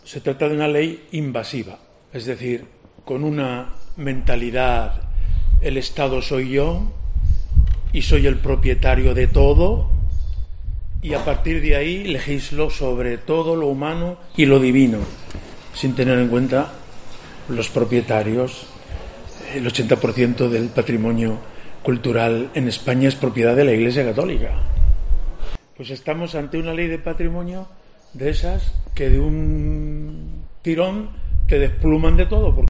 A este respecto y en rueda de prensa, Demetrio Fernández ha explicado que "se trata de una ley invasiva, es decir, con una mentalidad de: el Estado soy yo y soy el propietario de todo, y a partir de ahí legisló sobre todo lo humano y lo divino", actuando el Gobierno de la Nación "sin tener en cuenta a los propietarios", ni el hecho de que "el 80 por ciento del patrimonio cultural en España es propiedad de la Iglesia Católica".